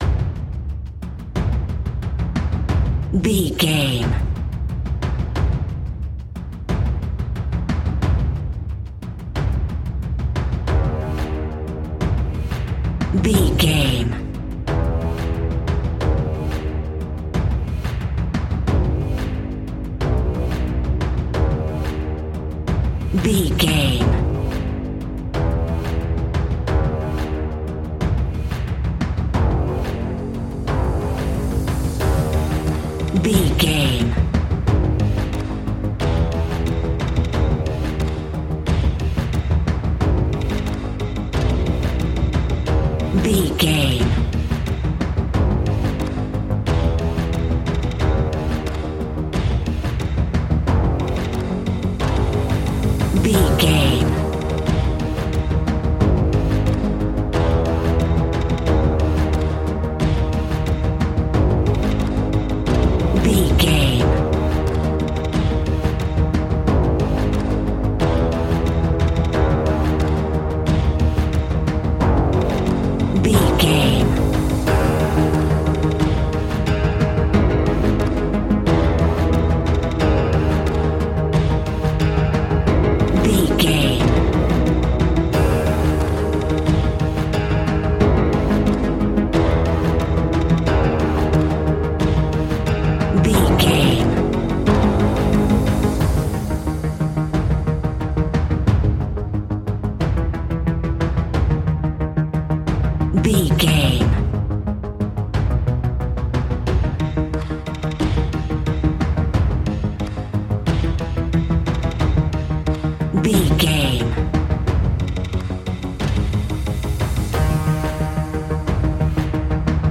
Aeolian/Minor
G#
ominous
dark
haunting
eerie
drums
percussion
synthesiser
ticking
electronic music
Horror Synths